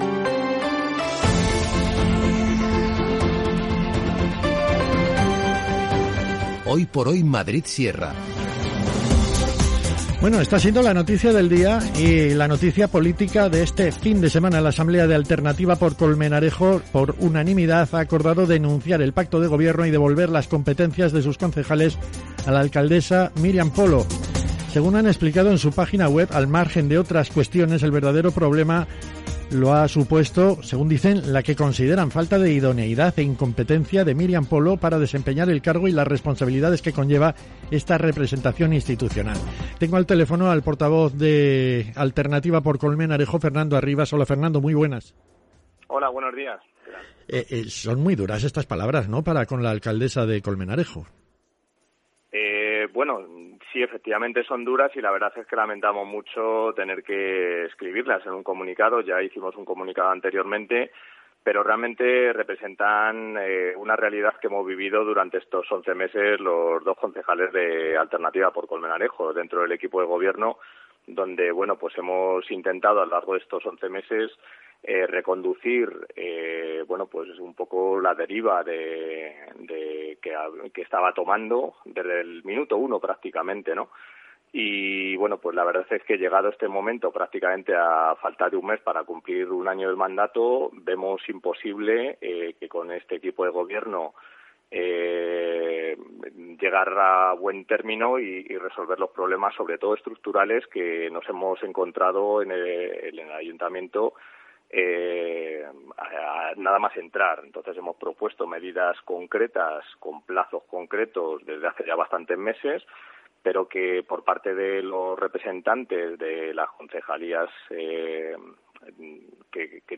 Fernando Arribas, concejal de AxC, es entrevistado por la SER acerca de la salida del Pacto de Gobierno